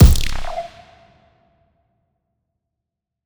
TM88 FunkKick3.wav